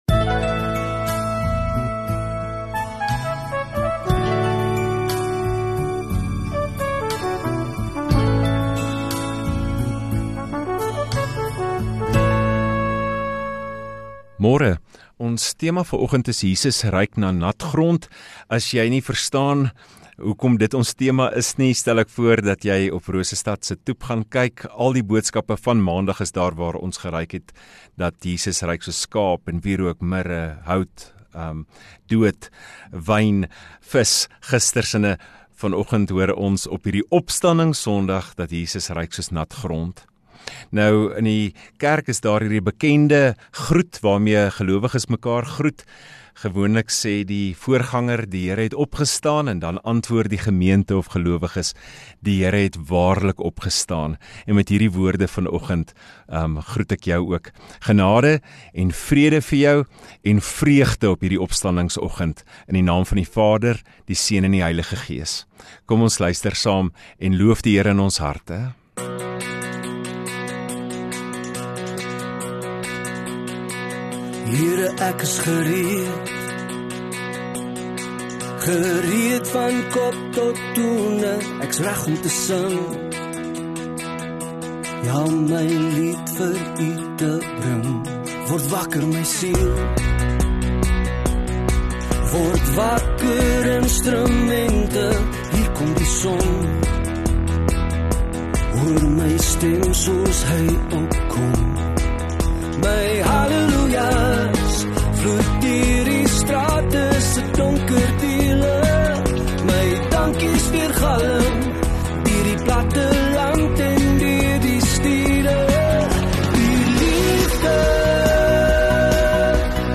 31 Mar Sondagoggend Erediens